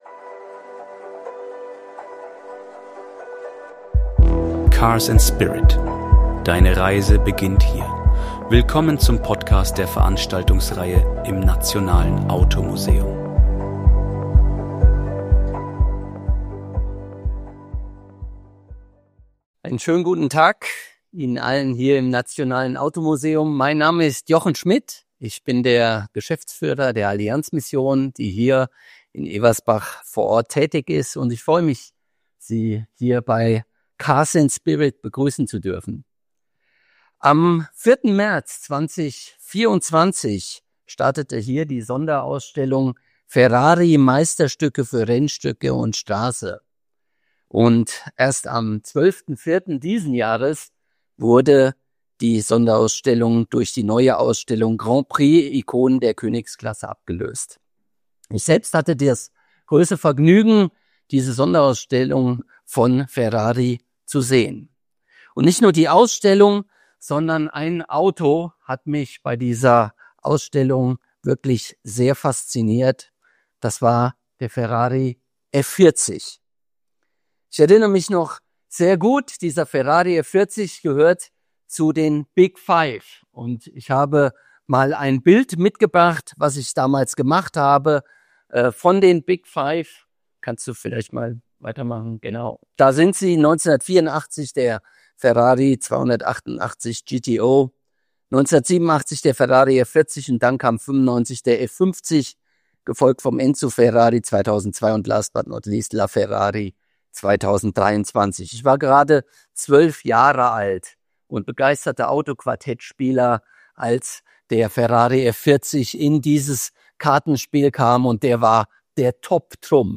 Willkommen im Nationalen Automuseum!